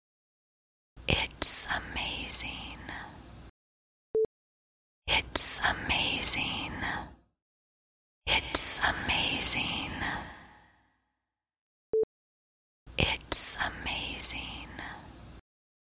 耳语 说话 说话 声乐 女性 女孩 女人 美国